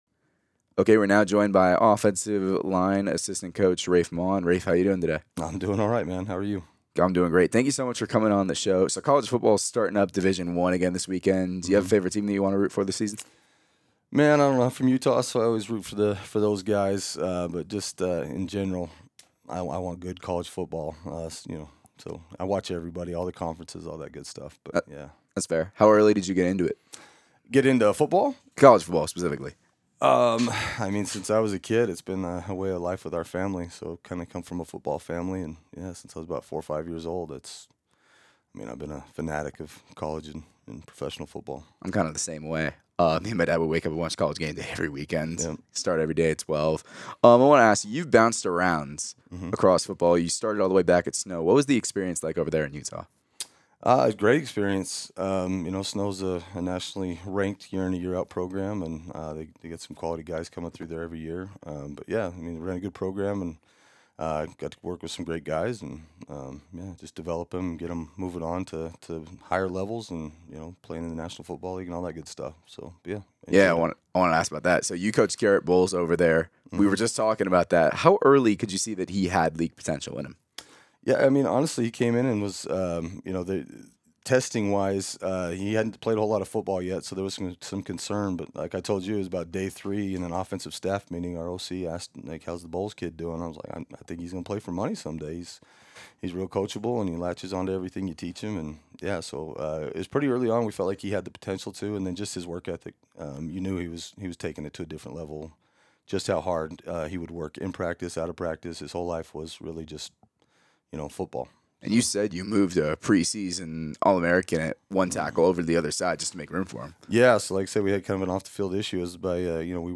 GARDEN CITY, KS. – The Garden City Community College Coaches Show restarted this Thursday live from Old Chicago Pizza and Taproom.
The Garden City Community College Coaches Show airs live every Thursday from 7:00 to 8:00 P.M. on KWKR 99.9 “The Rock”.